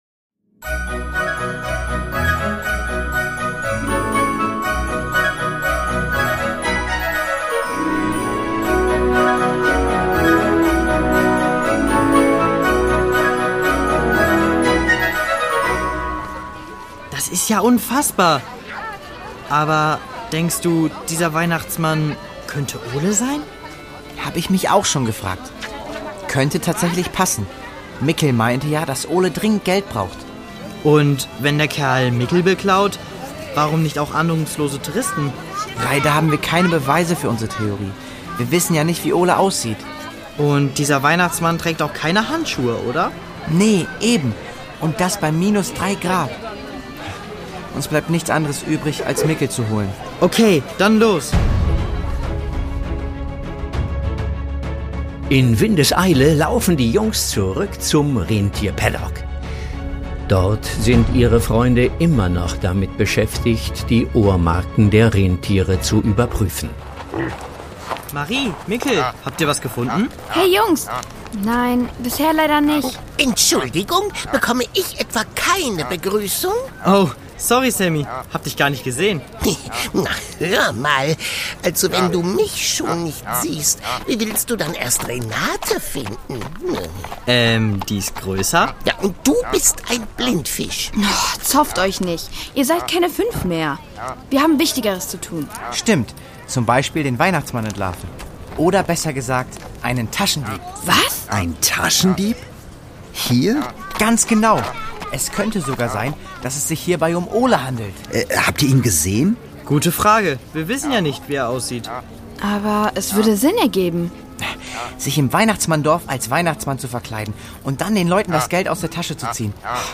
Lappland: Ein Rätsel im Schnee (19/24) | Die Doppeldecker Crew | Hörspiel für Kinder (Hörbuch)